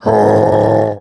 frank_vo_05.wav